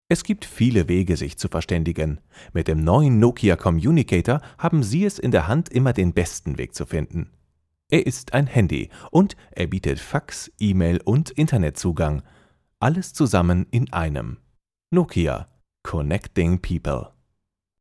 deutscher Sprecher, off Sprecher, TV Radio Moderator, Werbesprecher, Trailer, Hörbuch, Doku, Videospiele, div.
Sprechprobe: Industrie (Muttersprache):